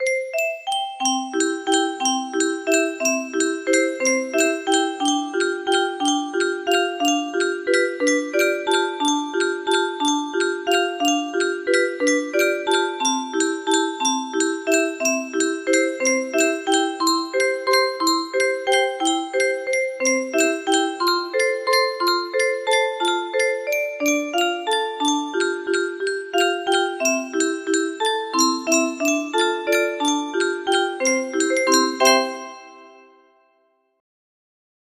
The Blue Danube - Richard Strauss music box melody